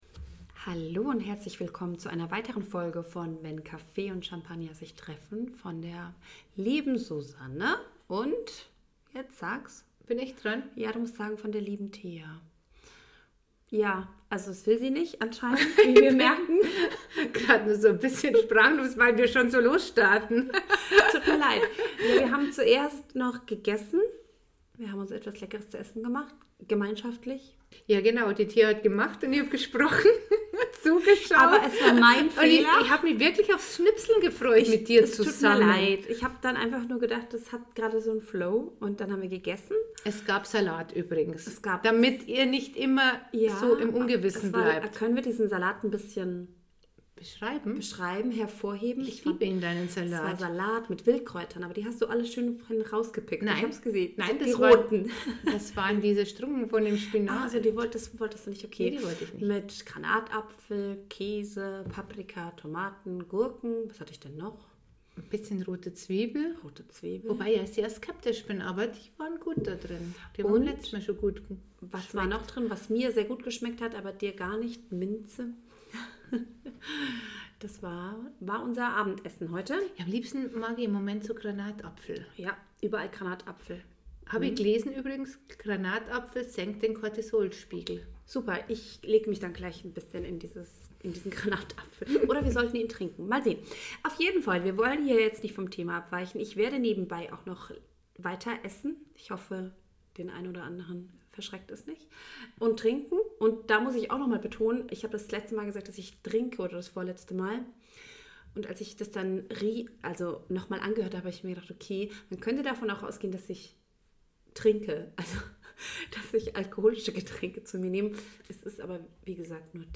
Zwei Frauen die dich in ihre Gedanken mitnehmen.